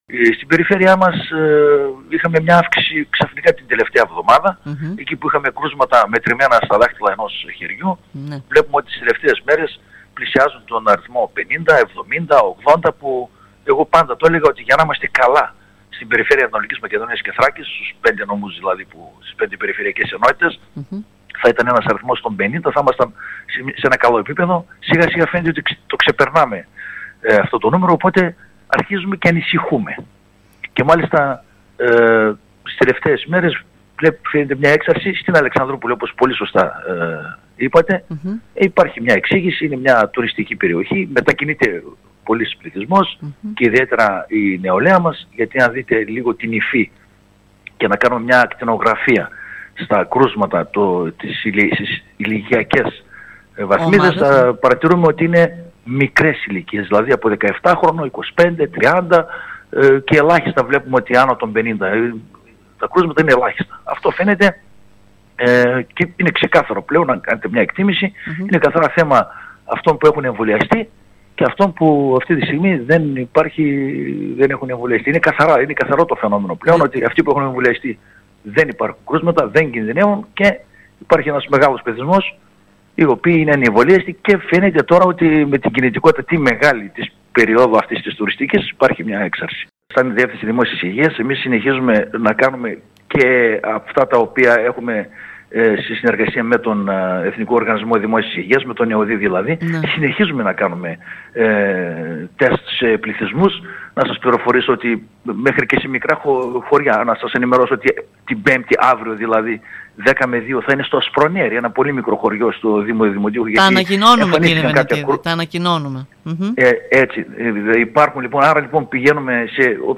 Την ιδιαίτερη ανησυχία του  για την έξαρση των κρουσμάτων  στην ΑΜ-Θ εξέφρασε ο θεματικός Αντιπεριφερειάρχης Υγείας Κώστας Βενετίδης
Μιλώντας σήμερα στην ΕΡΤ Ορεστιάδας,  δήλωσε χαρακτηριστικά πως στον Έβρο παρατηρείται μια αύξηση και αυτό έχει να κάνει  με την τουριστική κίνηση των ημερών.